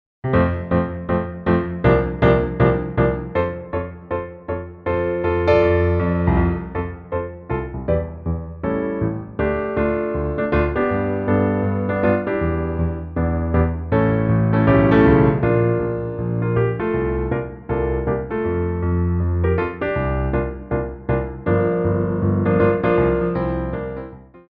Piano Arrangements of Popular Music
4/4 (16x8)